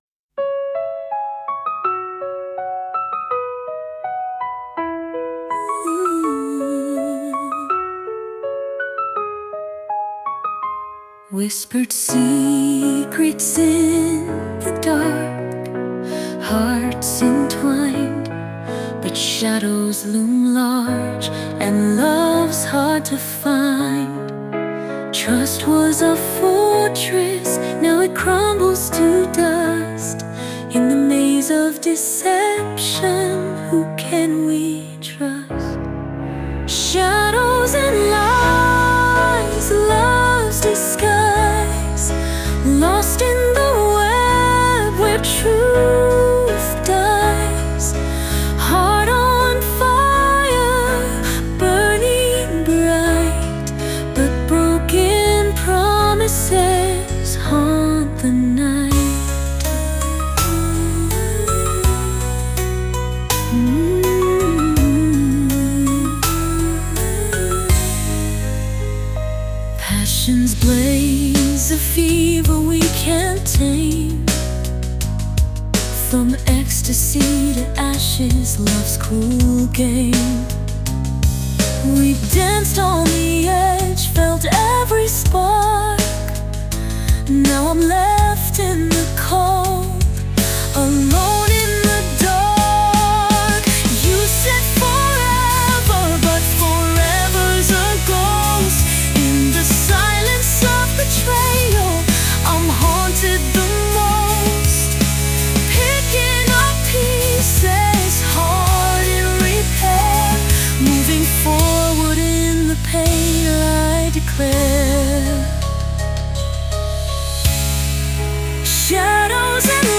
سبک : هیپ هاپ